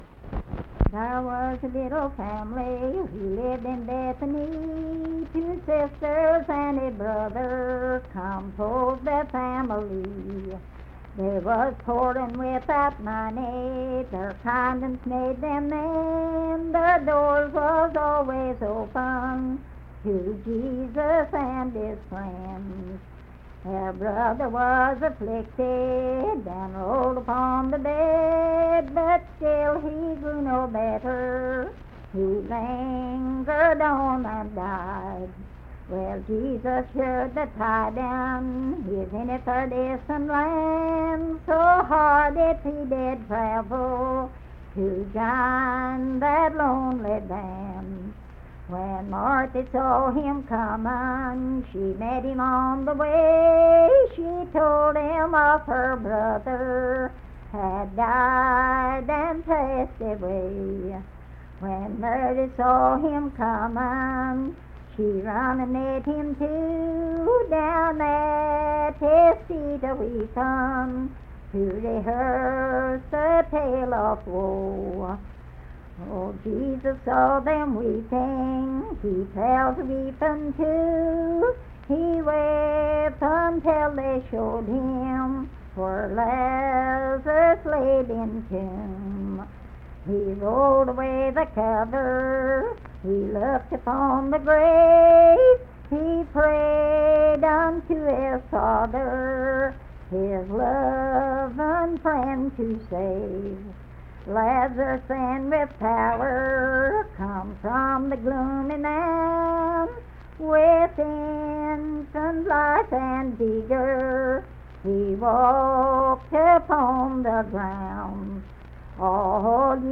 Unaccompanied vocal music
Verse-refrain, 10(4).
Hymns and Spiritual Music
Voice (sung)